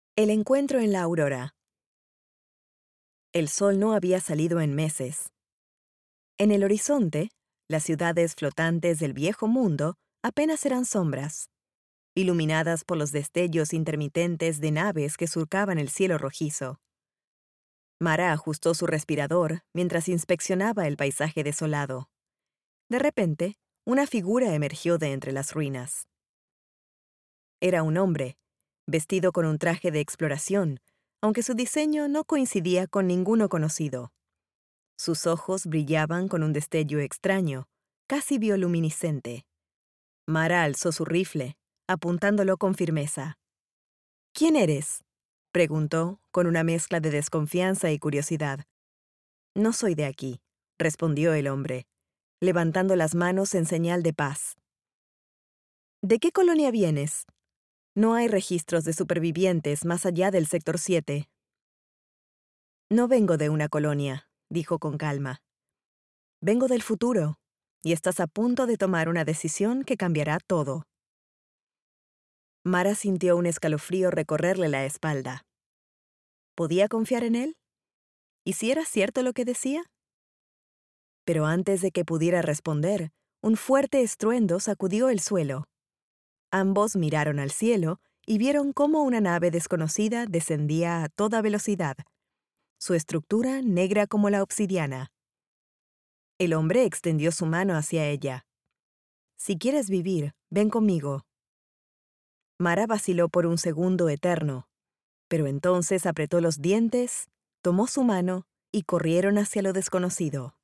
Female
Approachable, Character, Conversational, Corporate, Natural, Warm, Young
home studio sample.mp3
Microphone: Manley reference Cardioid